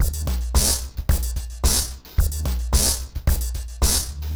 RemixedDrums_110BPM_19.wav